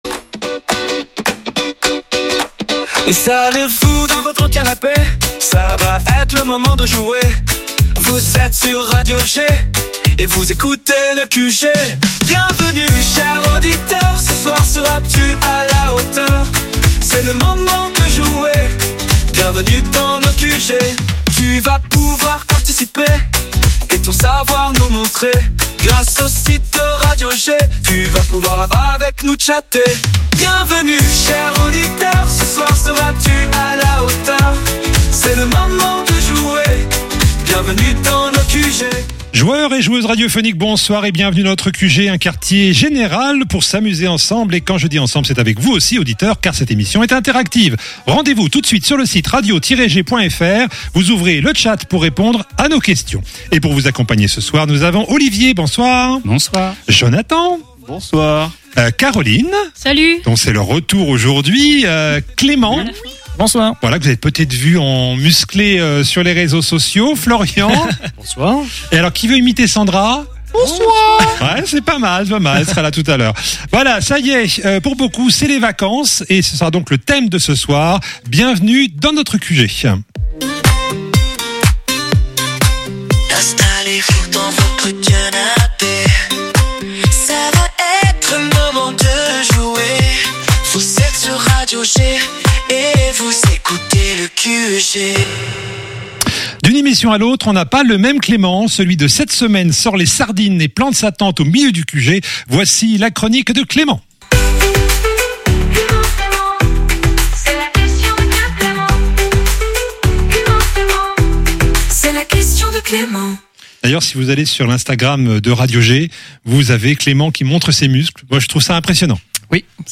Le QG, le programme radio de jeux de Radio G!